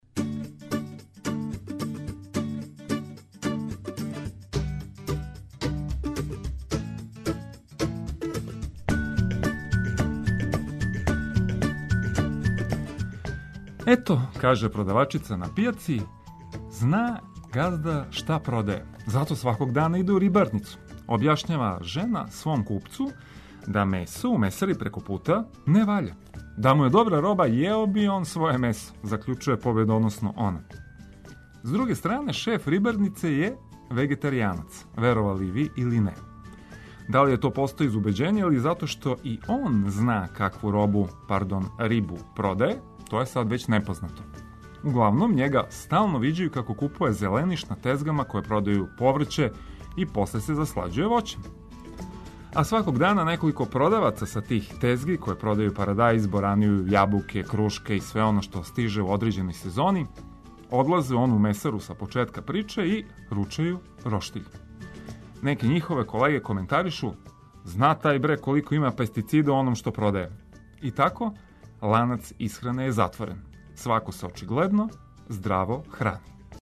Дочекајте дан уз важне али кратке информације и музику која расањује.